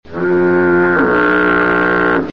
fog horn fard
fog horn
spongebob-fog-horn-made-with-Voicemod.mp3